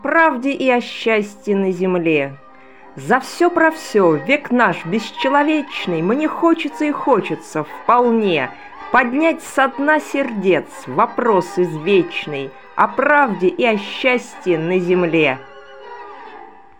Музыка классики